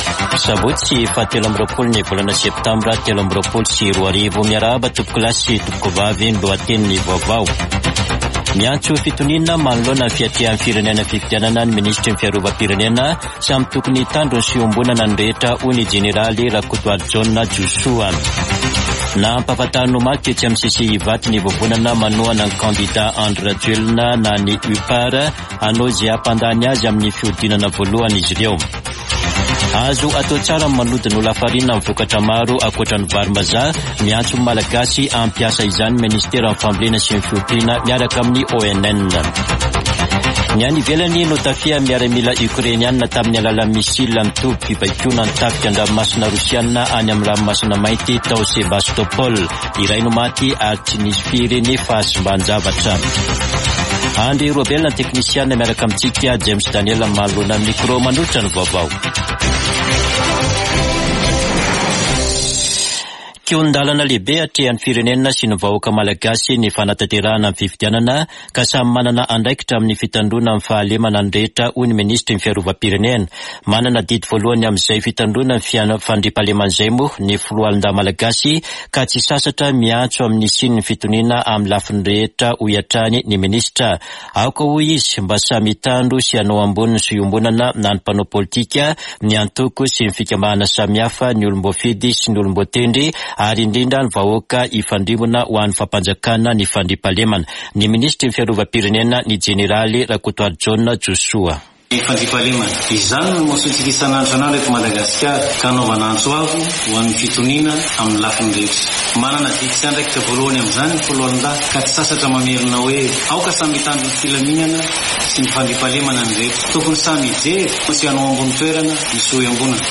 [Vaovao maraina] Sabotsy 23 septambra 2023